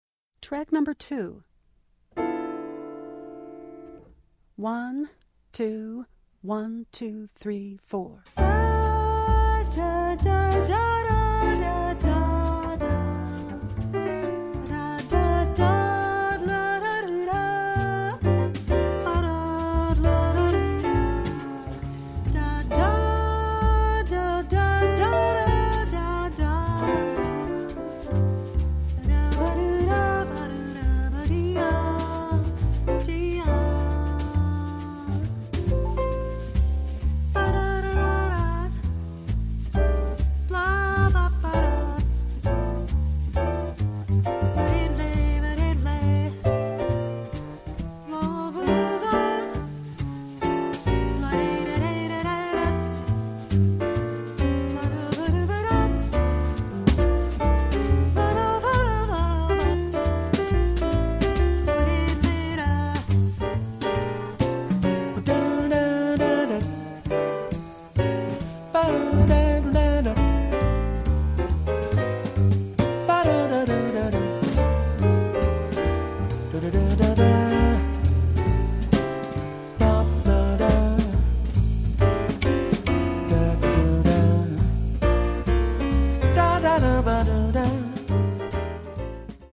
Voicing: Vocal